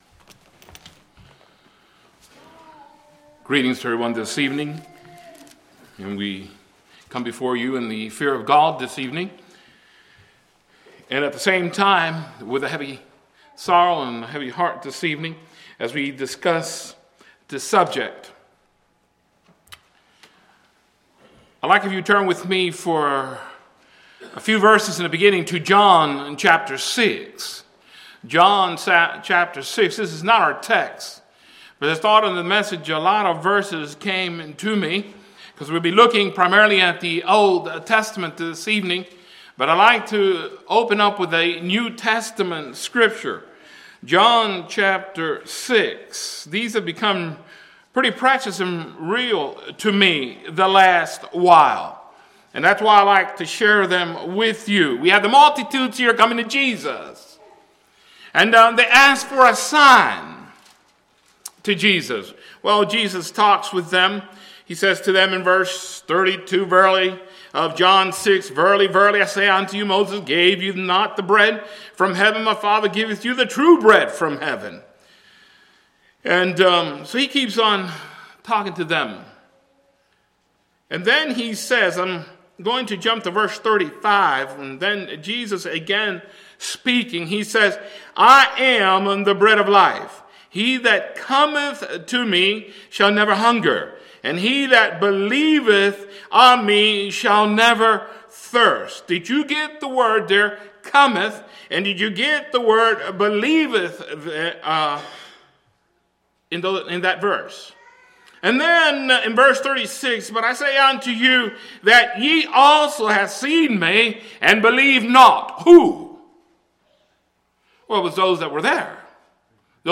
Sermon
Categories: Evangelistic